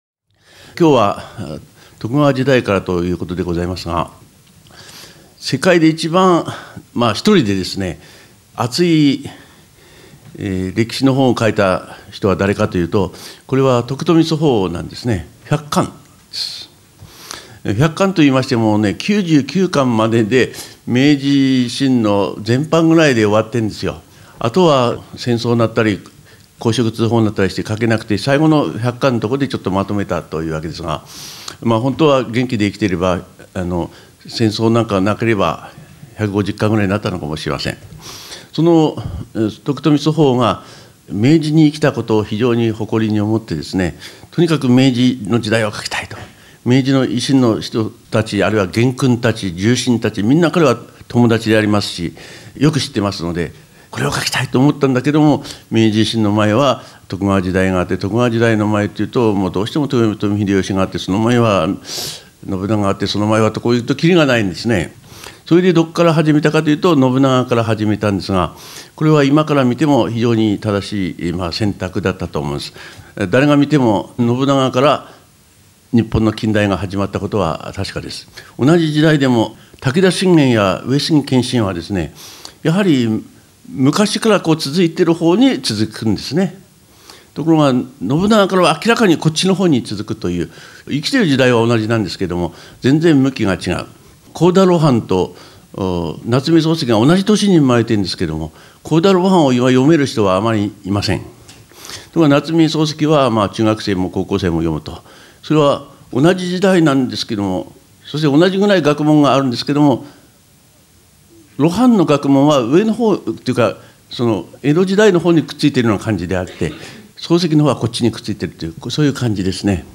歴史講座⑤